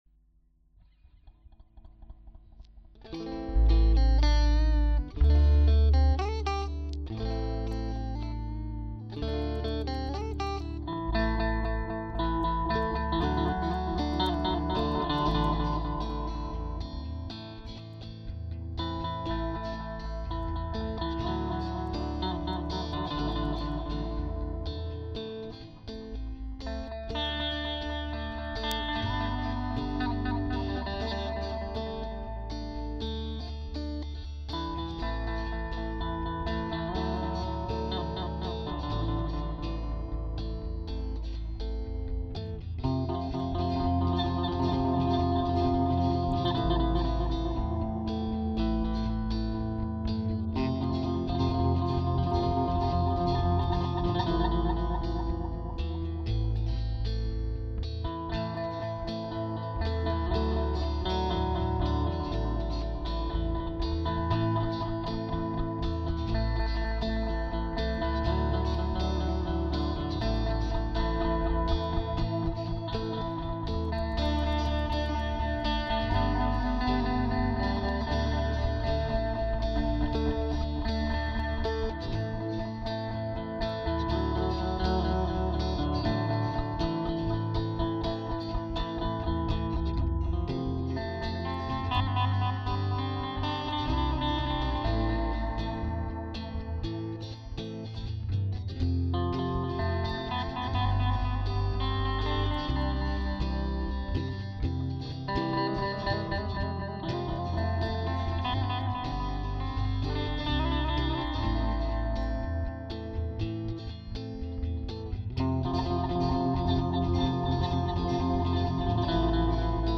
Beaucoup d'écho cho chocho cho cho